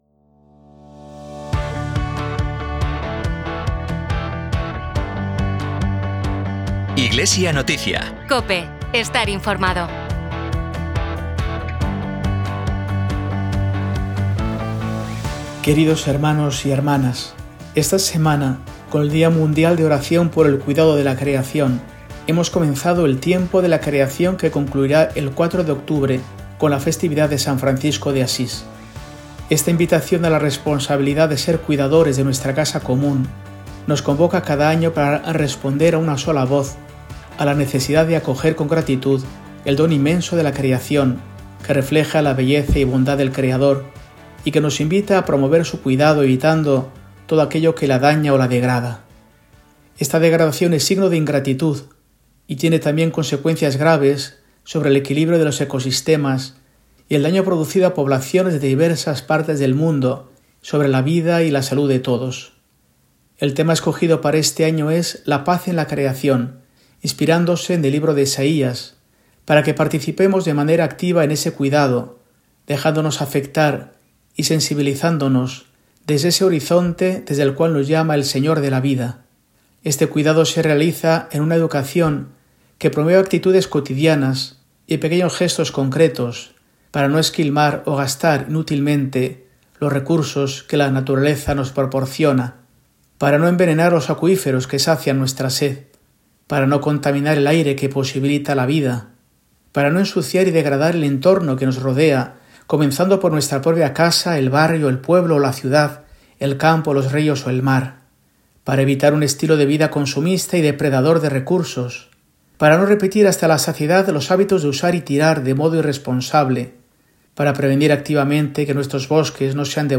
Mensaje semanal de Mons. Mario Iceta Gavicagogeascoa, arzobispo de Burgos, para el domingo, 7 de septiembre de 2025, XXIII del Tiempo Ordinario